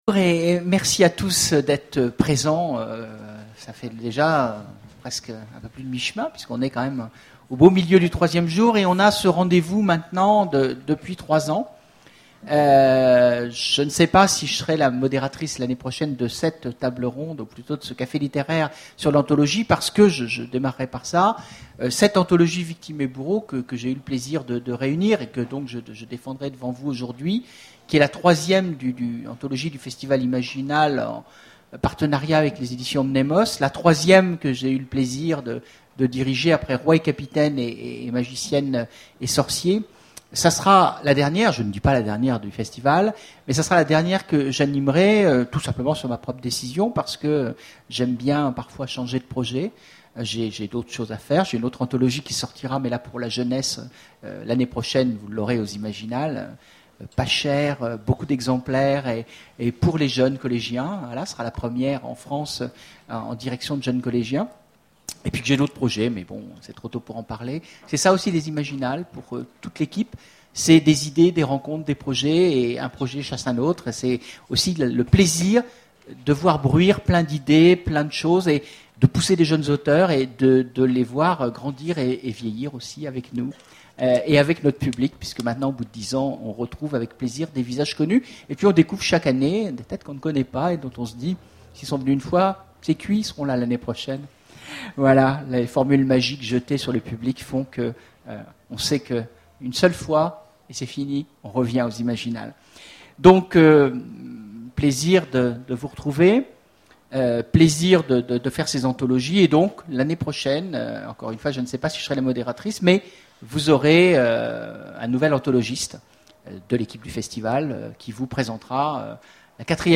Imaginales 2011 : Conférence sur l'anthologie du festival
Voici l'enregistrement de la conférence sur l'anthologie du festival, Victimes et Bourreaux...